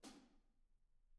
R_B Hi-Hat 04 - Room.wav